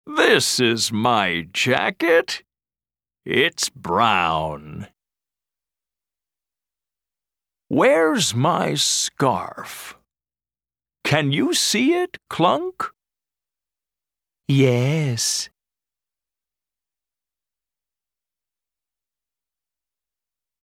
Track 2 Where's My Hat US English.mp3